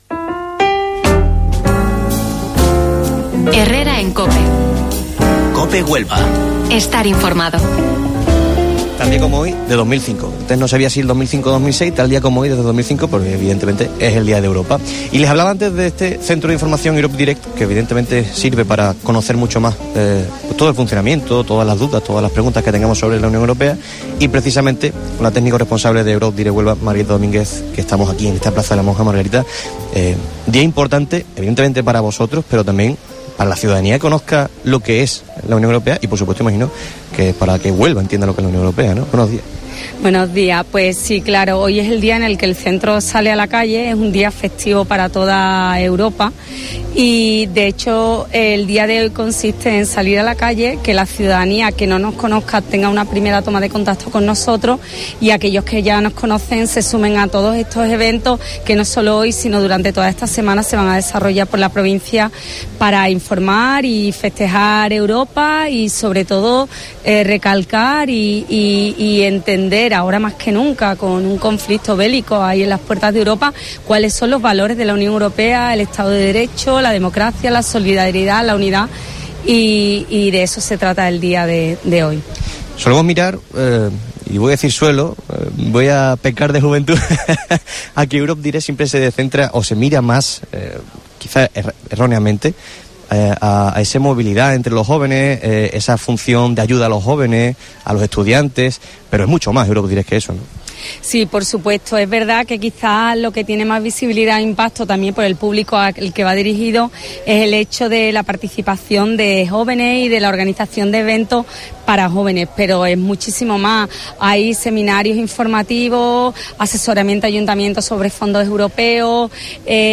Desde la céntrica Plaza de las Monjas de la capital realizamos una entrevista